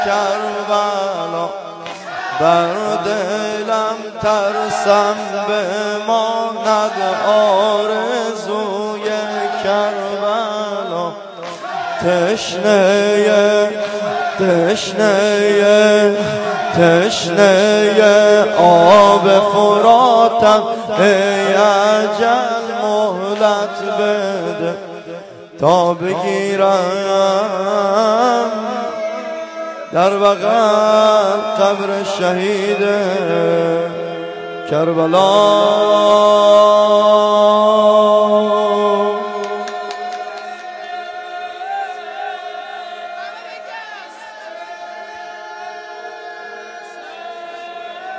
جلسه هفتگی 26-9-93.mp3